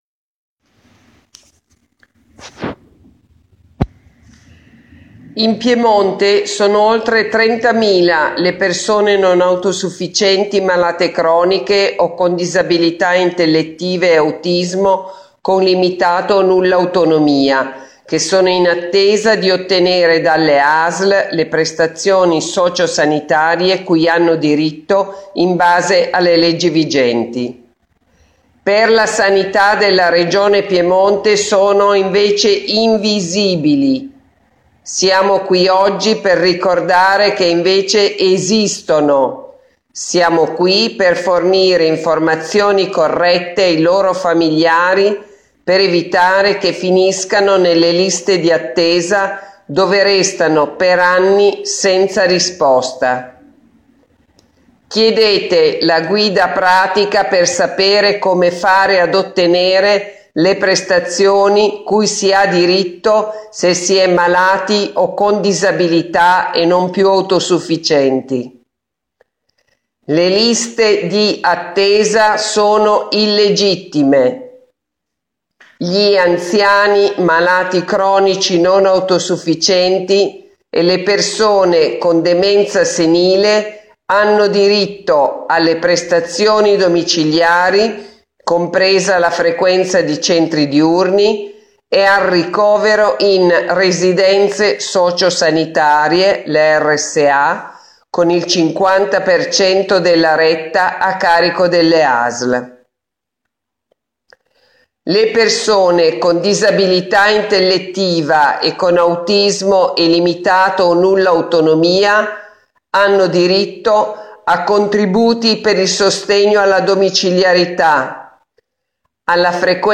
Messaggio audio diffuso nel corso dei presidi (file audio)